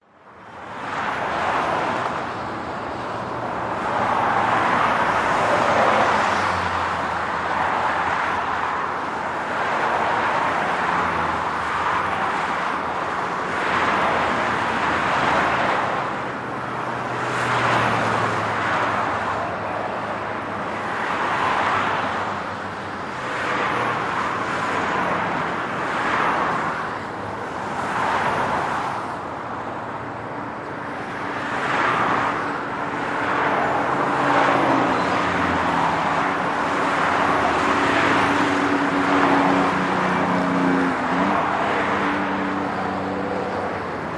Just the noise of cars.mp3